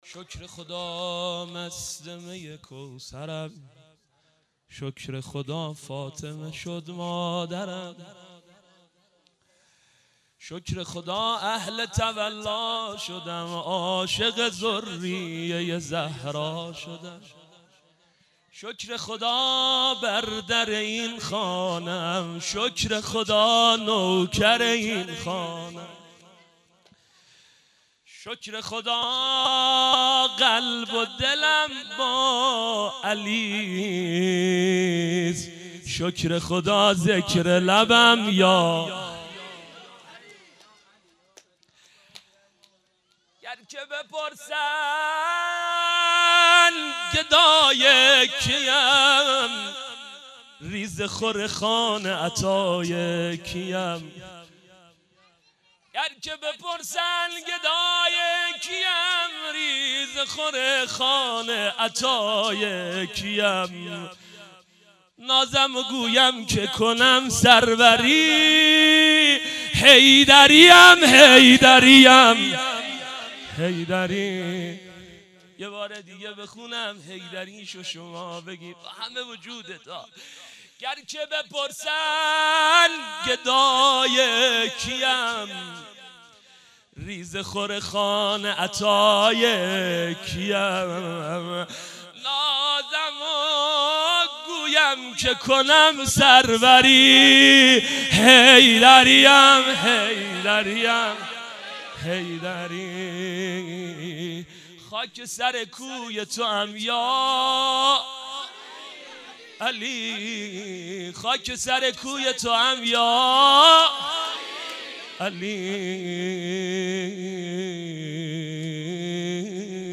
فاطمیه97- مجمع دلسوختگان بقیع- شب چهارم- روضه پایانی